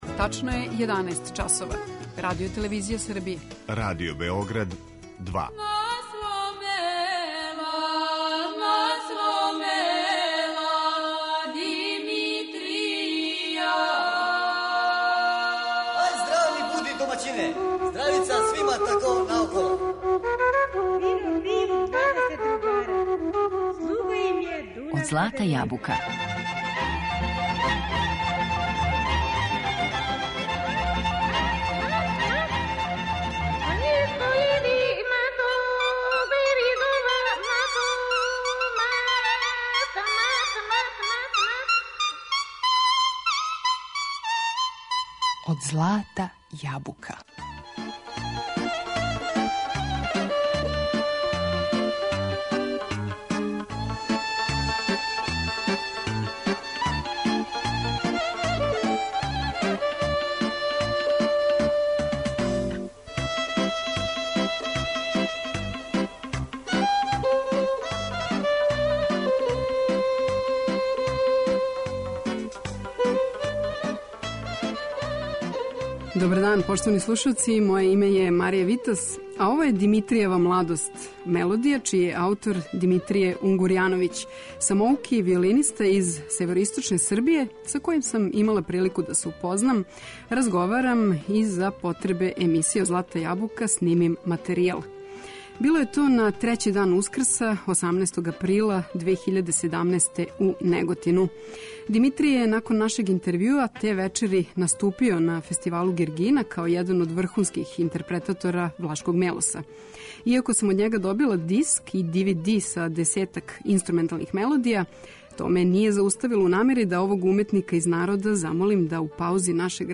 влашка виолина